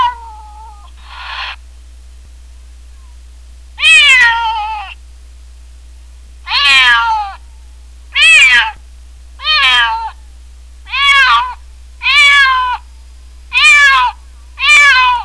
Distressed House Cat